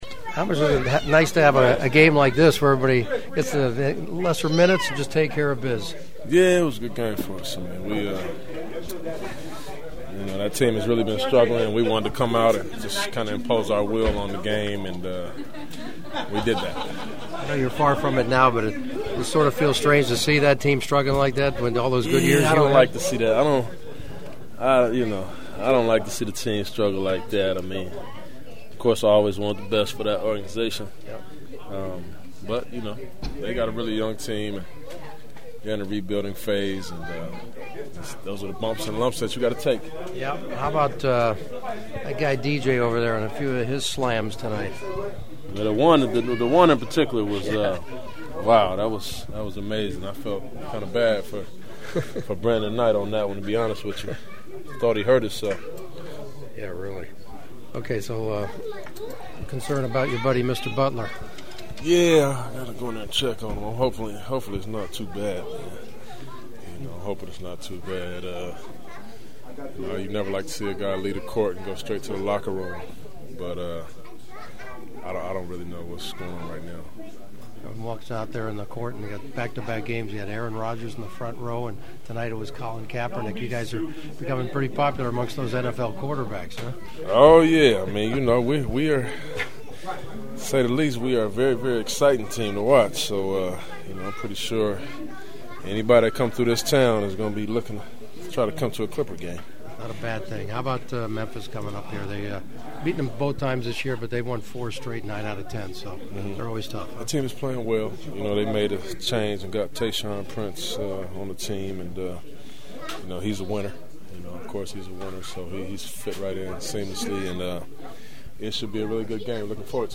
The following is my postgame locker room chatter with the winning Clippers who are now an impressive 25 games over .500 before hosting Memphis on Wednesday night (which you can always hear on KFWB Newstalk 980).